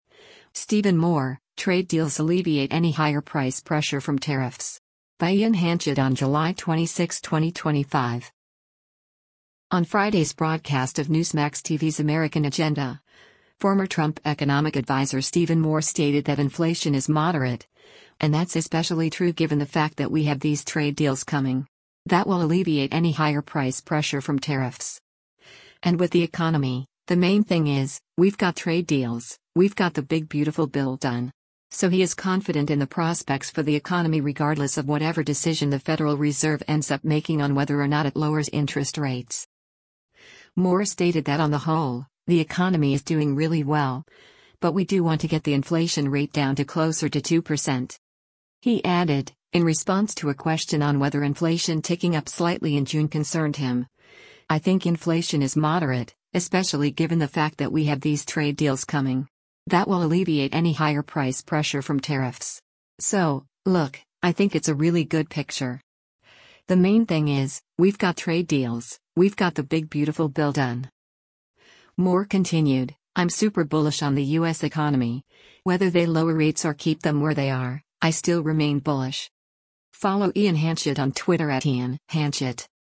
On Friday’s broadcast of Newsmax TV’s “American Agenda,” former Trump Economic Adviser Stephen Moore stated that “inflation is moderate,” and that’s especially true “given the fact that we have these trade deals coming. That will alleviate any higher price pressure from tariffs.”